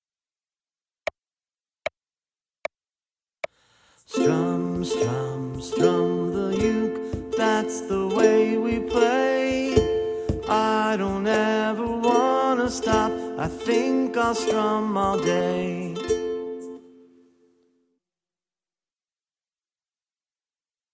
Genre children's songs
• Instrumentation: Ukulele